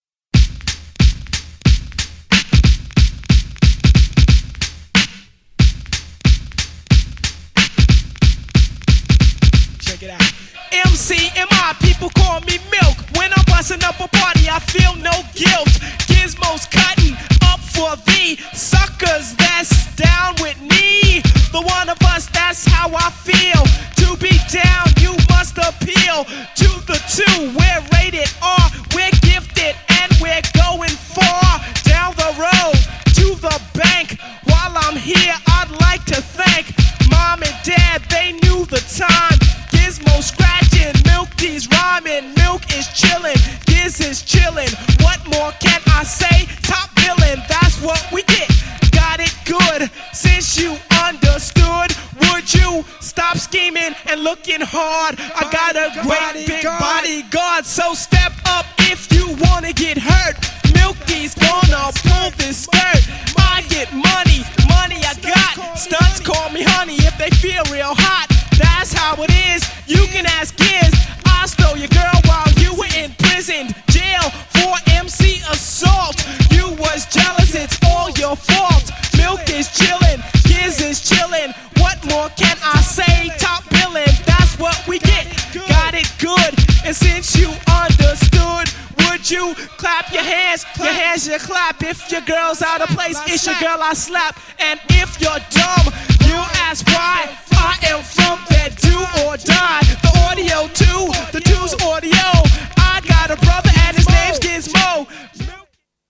GENRE Hip Hop
BPM 91〜95BPM
# 打ち付けるビートがカッコイイ # 間違いなくHIPHOP_CLASSIC